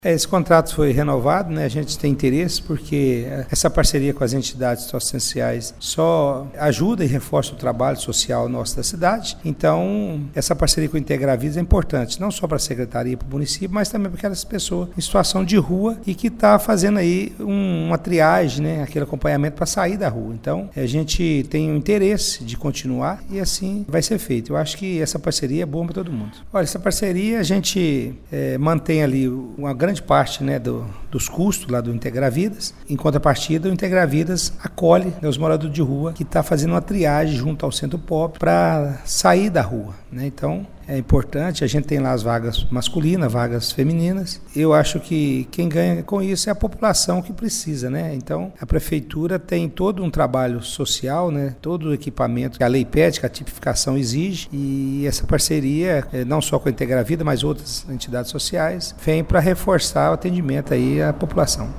Vilson Antônio dos Santos, secretário municipal de Assistência e Desenvolvimento Social, diz que o convênio foi renovado com a entidade para que o trabalho com os moradores de rua sejam acolhidos durante a noite: